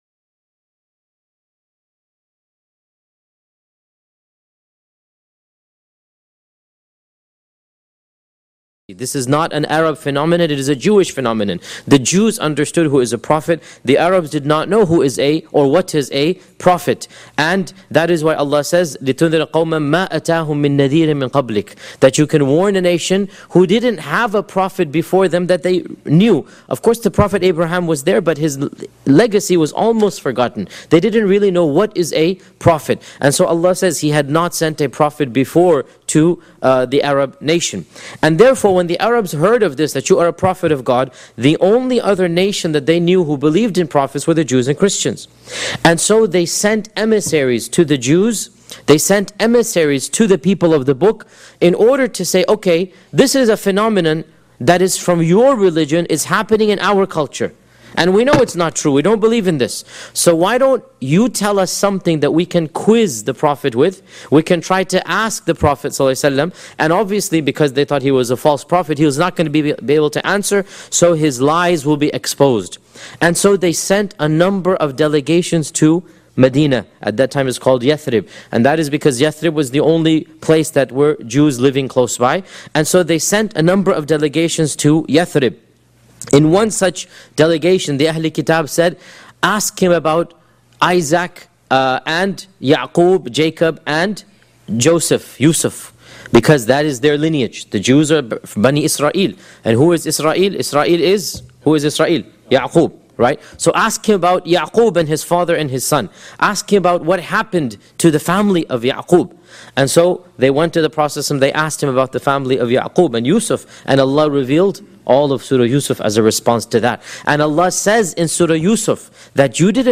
399 views High Quality: Download (247.99 MB) Medium Quality: Download (36.58 MB) MP3 Audio (01:07:54): Download (53.94 MB) Transcript: Download (0.31 MB) Seerah of Prophet Muhammad 13 Shaykh Yasir Qadhi gives a detailed analysis of the life of Prophet Muhammed (peace be upon him) from the original sources. Title: Torture and persecution of the weak Study the biography of the single greatest human being that ever walked the surface of this earth, whom Allah sent as a Mercy to Mankind. This lecture was recorded on 9th November, 2011 Shaykh Yasir Qadhi gives a detailed analysis of the life of Prophet Muhammed (peace be upon him) from the original sources.